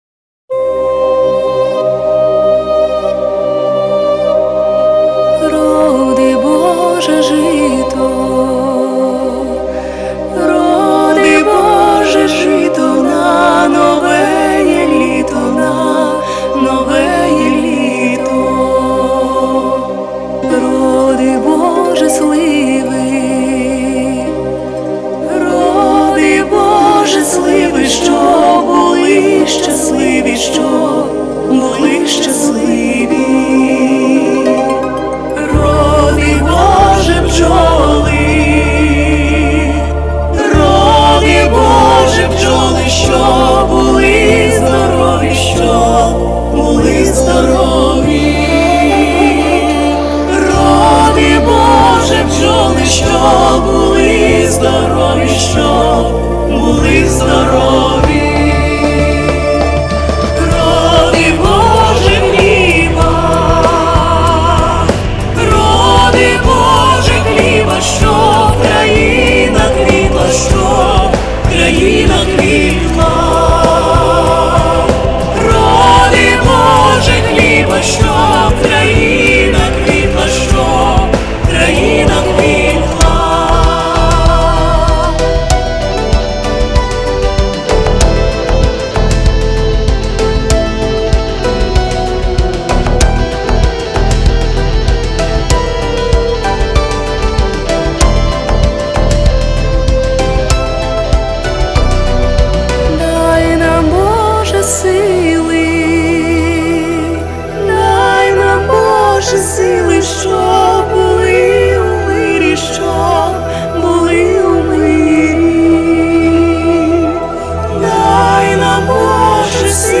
Студійна мінусовка
Folk